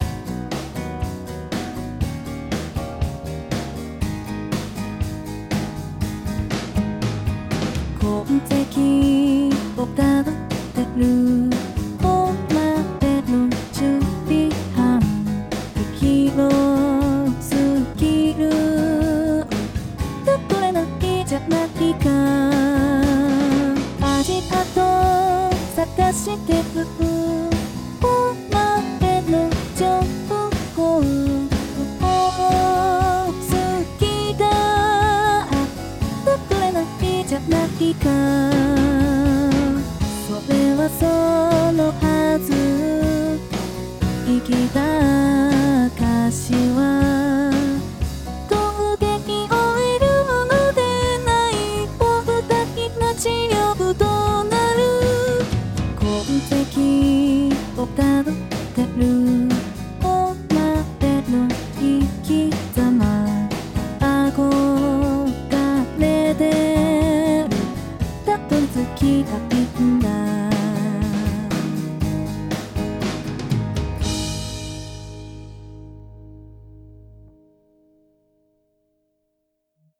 No.01225 [歌]